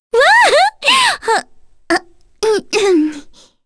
Kirze-Vox_Happy4_kr.wav